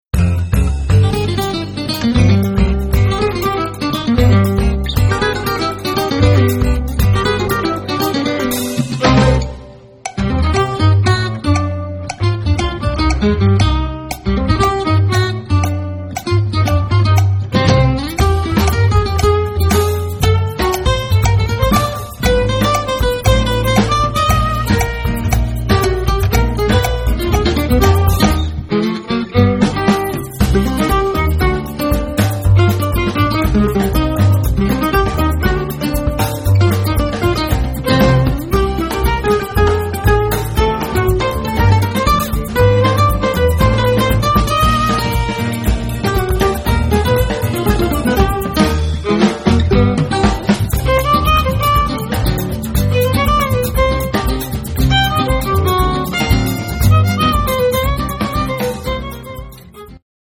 and a jazz-tinged rumba
rhythm and fingerstyle guitar, vocals
lead guitar, mandolin, vocals
double-bass, vocals
drums, percussion
fiddle, vocals
Acoustic
Americana
Jamband